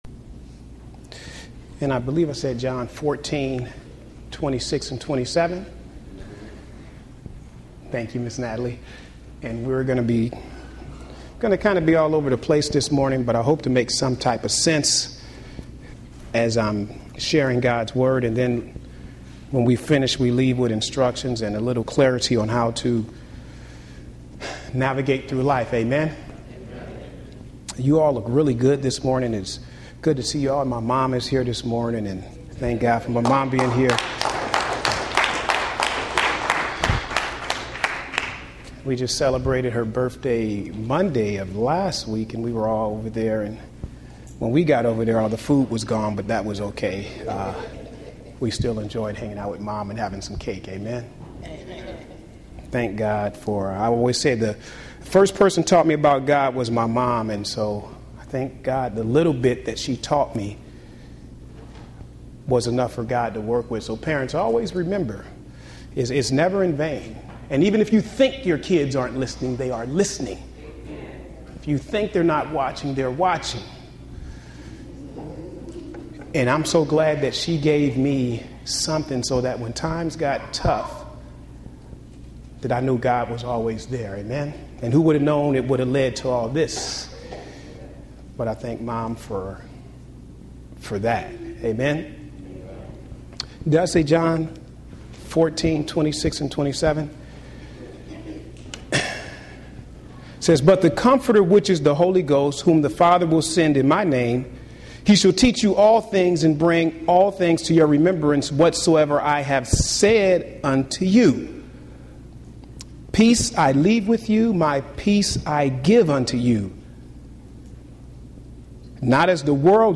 Home › Sermons › Love Peace Holy Spirit